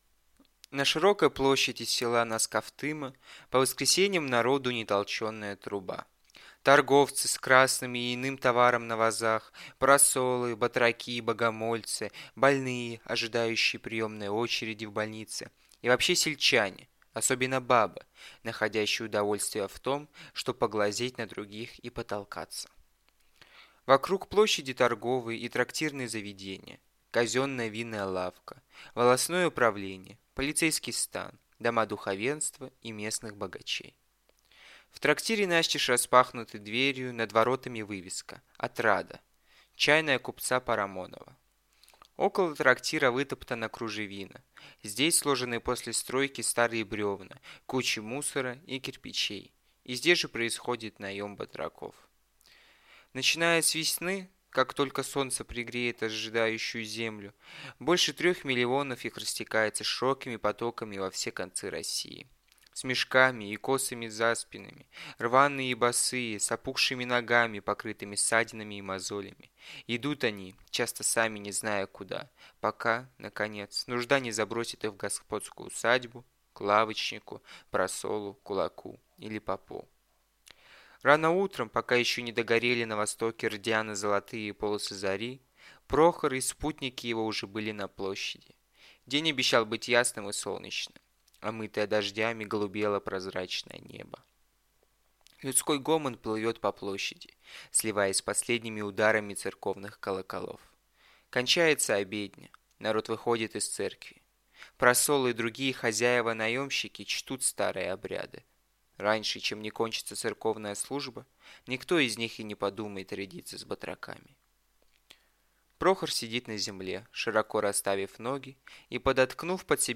Аудиокнига Бунт | Библиотека аудиокниг